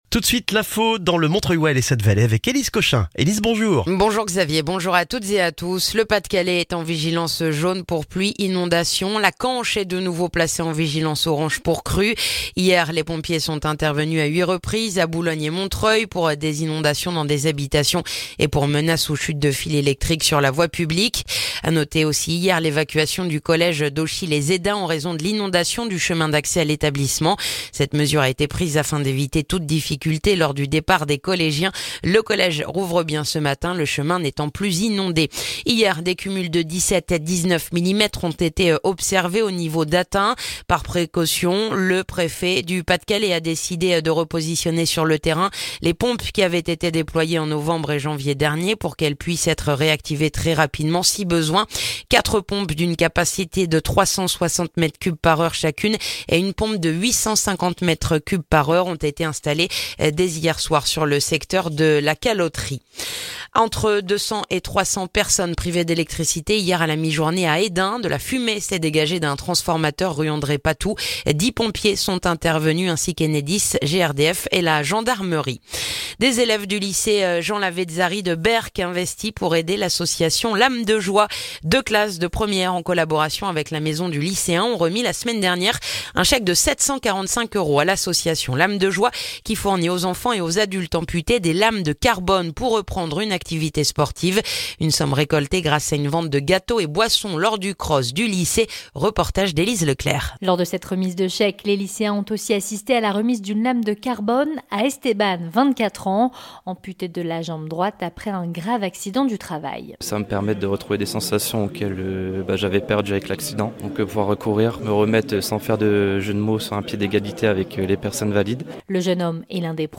Le journal du jeudi 8 février dans le montreuillois